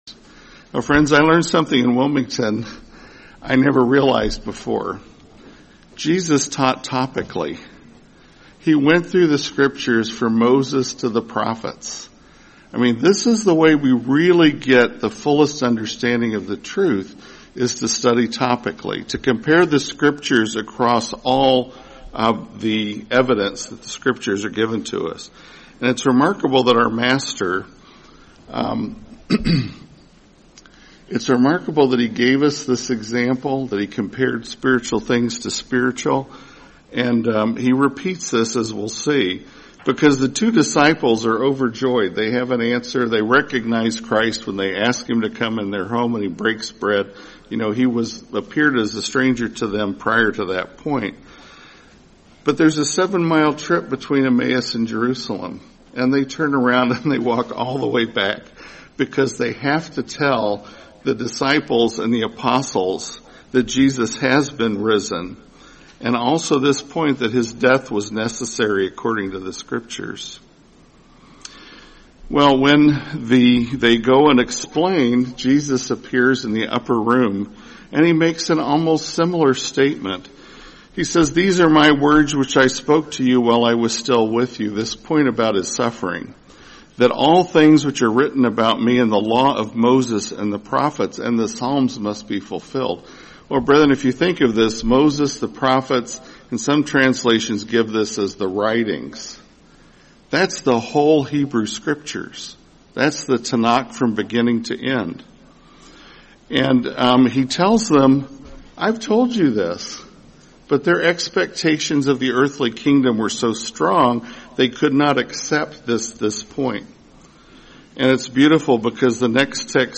Series: 2026 Florida Convention